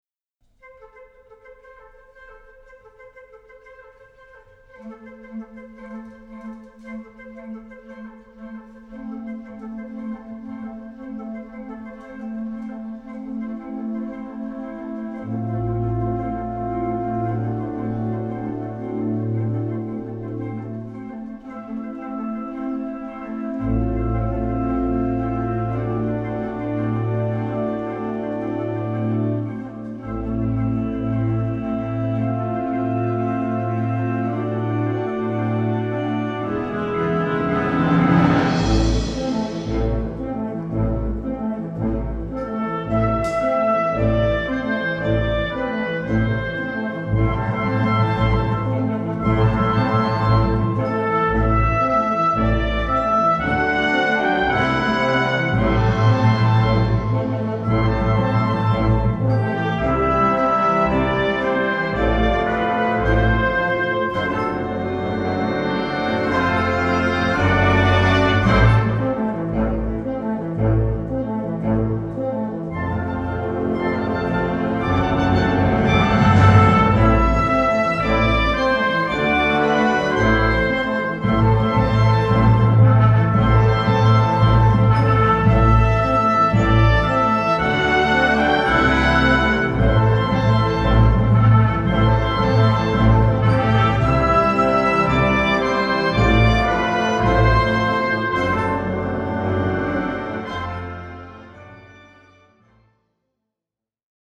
Wind Band (harmonie)
Easy Listening / Unterhaltung / Variété